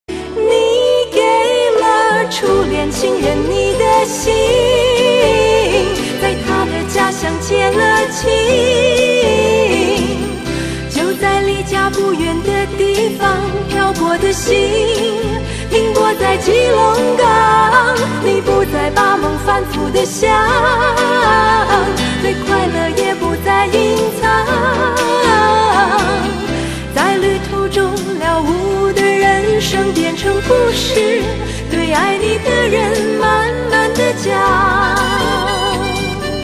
M4R铃声, MP3铃声, 华语歌曲 20 首发日期：2018-05-15 08:18 星期二